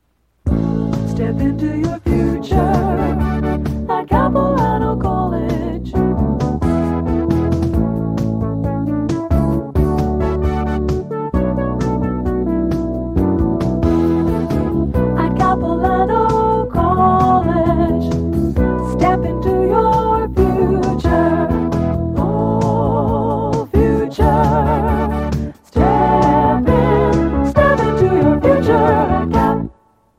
Promotional Audio/Radio Jingle
audio cassette